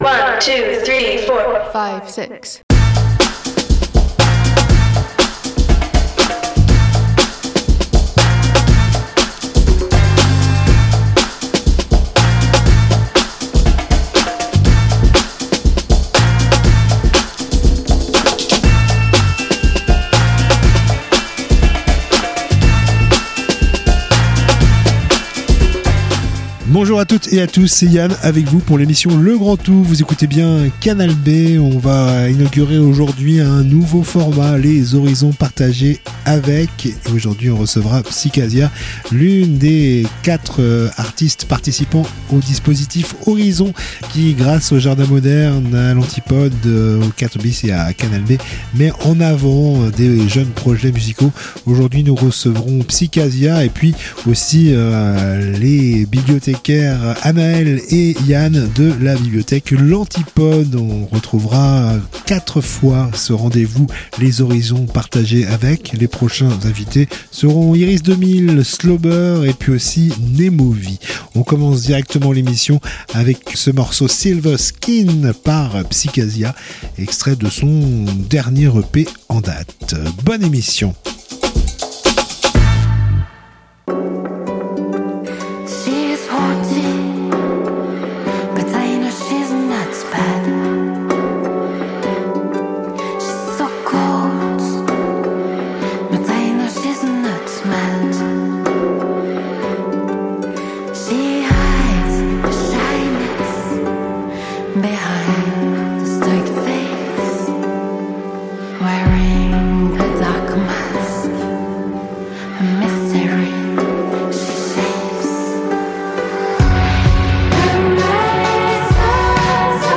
Les Horizons Partagés avec... c'est un nouveau format dans Le Grand Tout , des découvertes musicales croisées en 8 titres, 4 choisis par les artistes et 4 choisis par les bibliothécaires de la Bibli Antipode, ces derniers inspirés par les choix des artistes.